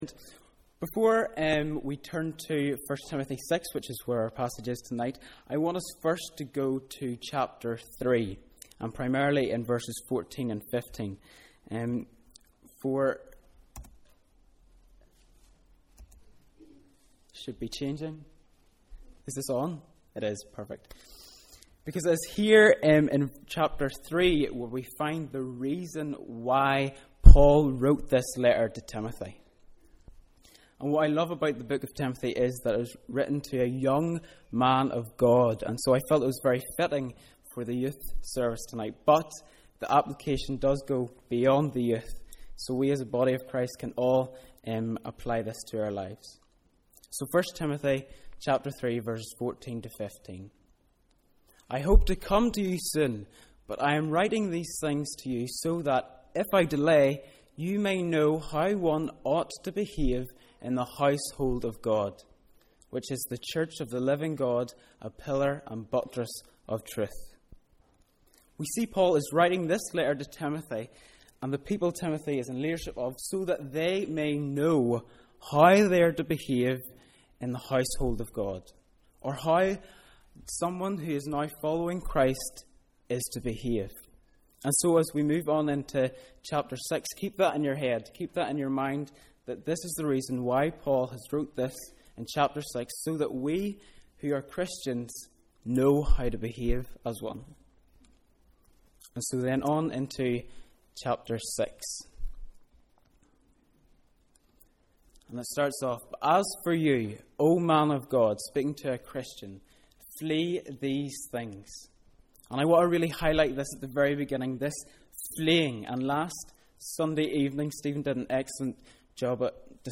Youth Service
Evening Service: Sunday 19th May 2013